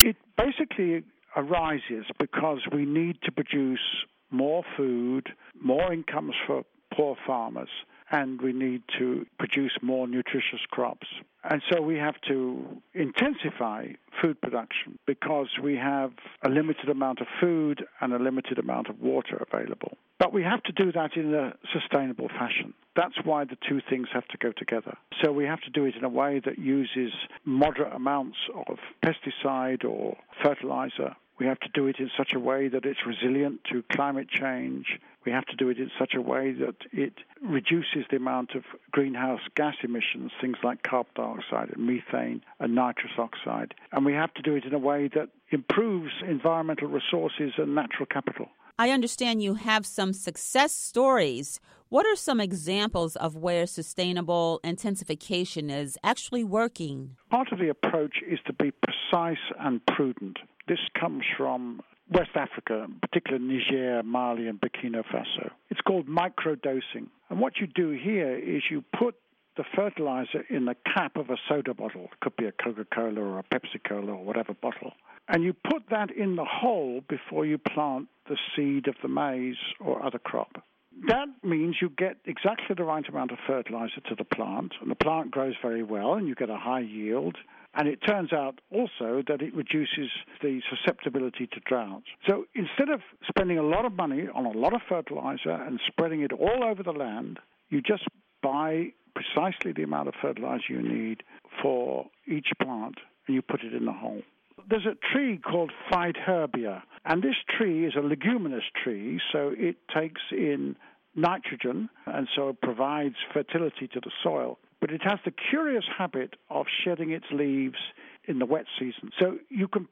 Interview with Sir Gordon Conway